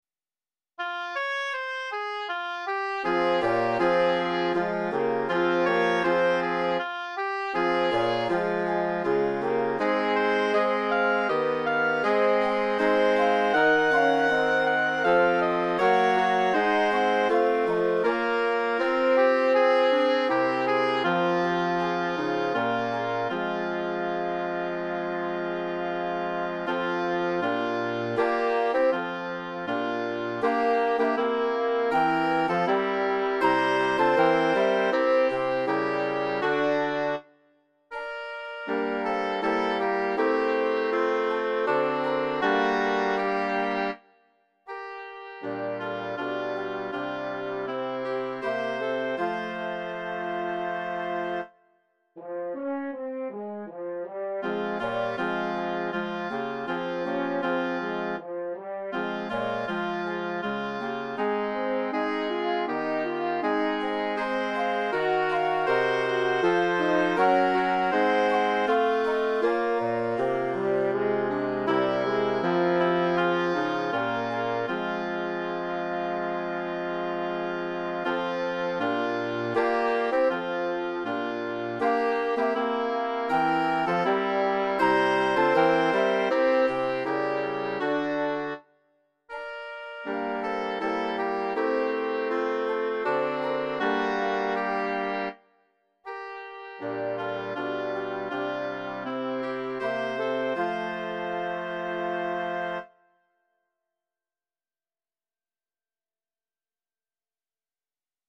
WOODWIND QUINTET